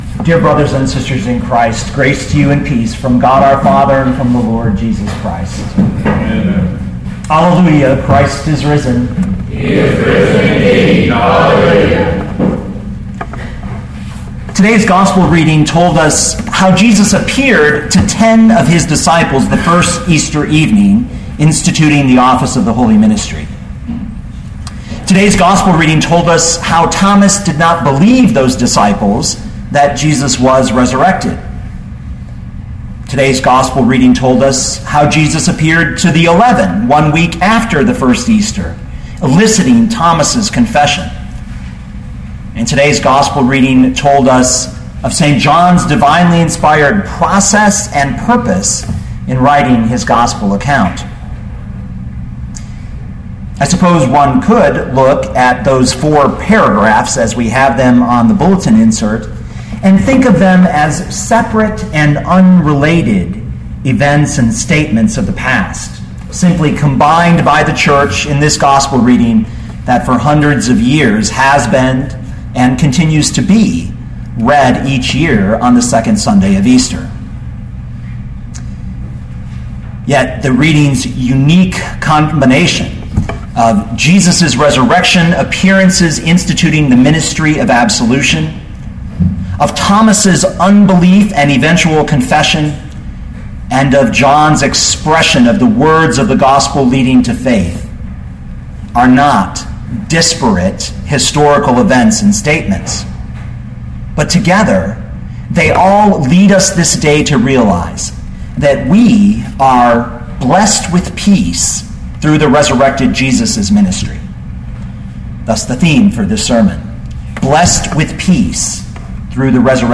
2014 John 20:19-31 Listen to the sermon with the player below, or, download the audio.